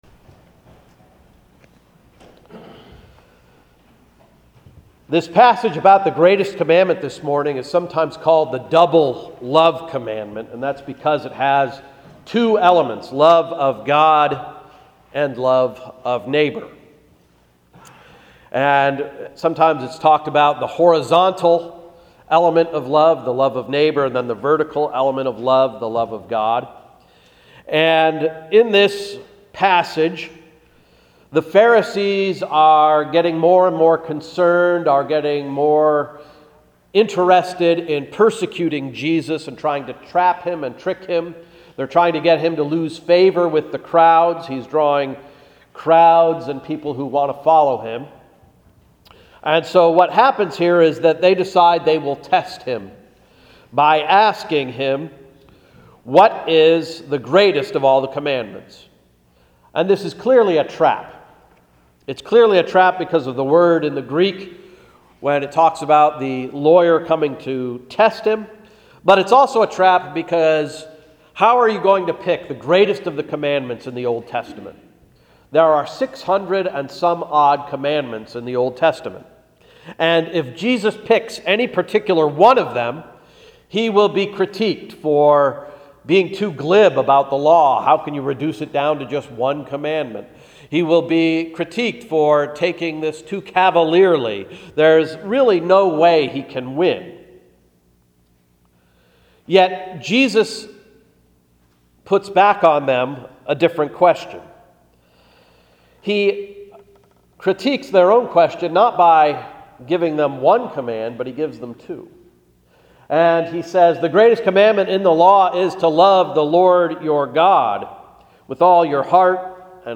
Sermon of October 26, 2014–“Love’s Redeeming Work is NOT Done”